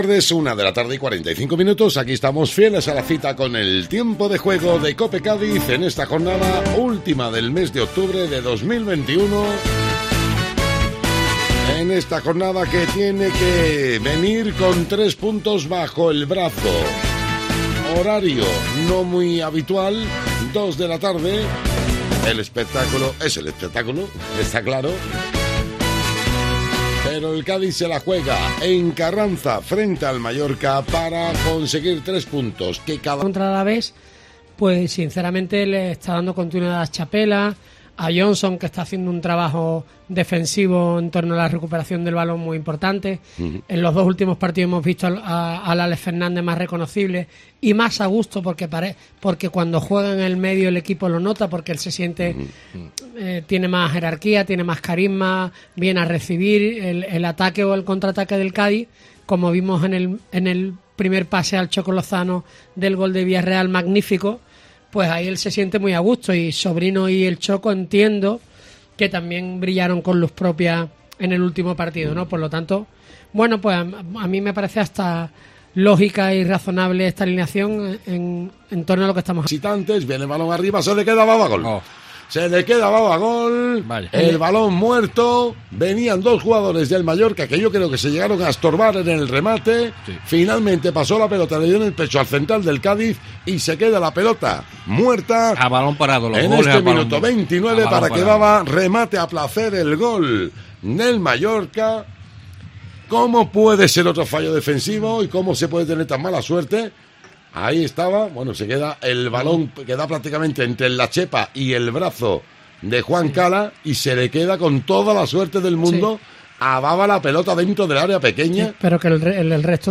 El resumen sonoro del Cádiz 1-1 Mallorca en COPE Cádiz